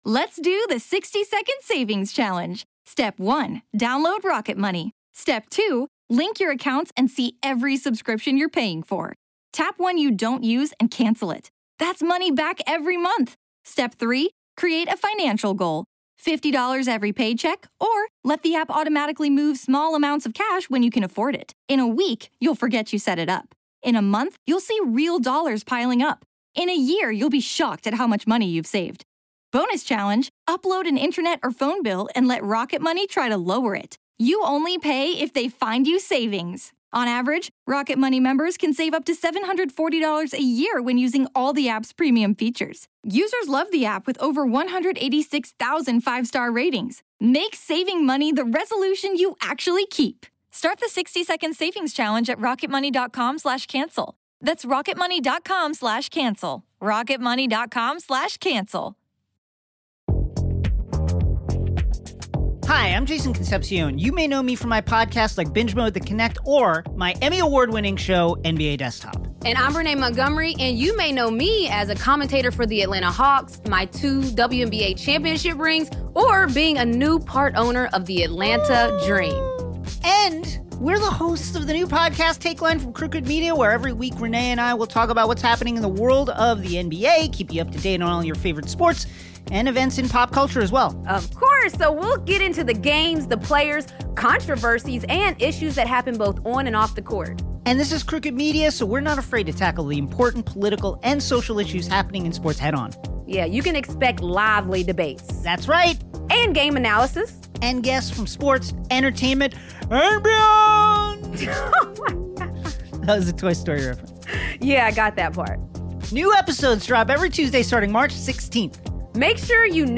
Introducing Takeline, the newest podcast from Crooked Media. Takeline is a weekly podcast hosted by Jason Concepcion that’s a fast-paced exploration of the NBA and world of sports and culture.
The show will be a place for lively debates, guests (experts and super fans), and contributors who will provide game analysis, get deep into the storylines and will give the audience a chance to learn everything they didn’t even know about the games.